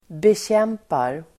Uttal: [betj'em:par]